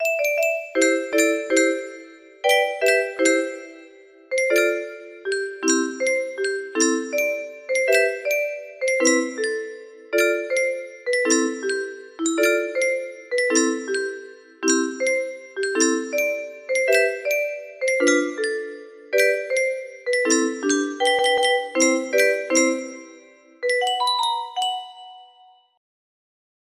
30619 music box melody